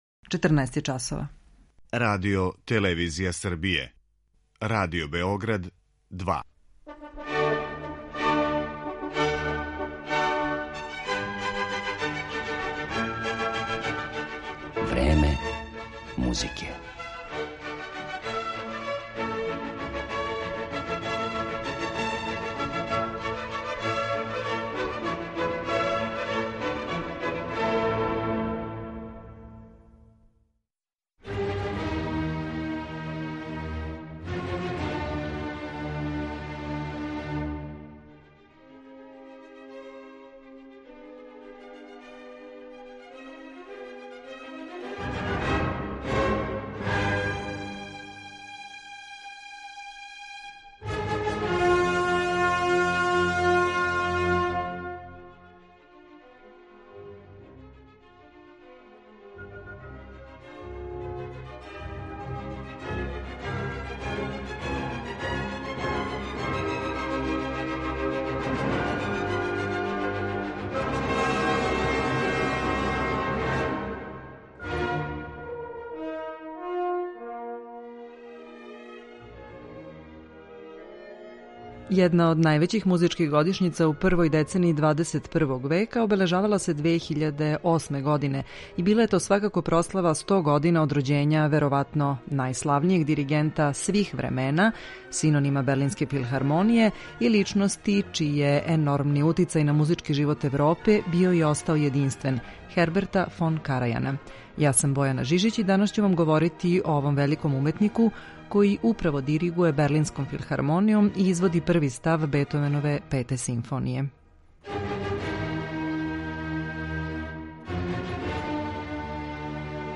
Pored izvrsnih umetnikovih tumačenja pre svega nemačke muzike, moći ćete da čujete i intervju sa Karajanom objavljen u britanskom muzičkom časopisu „Gramofon" 2008. godine, kada je obeležavano 100 godina od rođenja ovog velikog dirigenta.